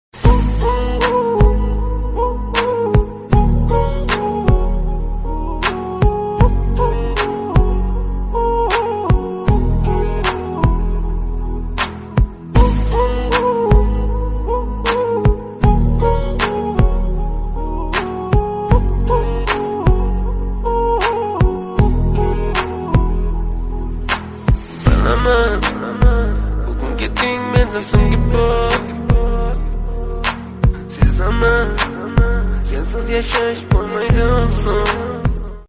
آهنگ زنگ موبایل لاتی غمگین ریمیکس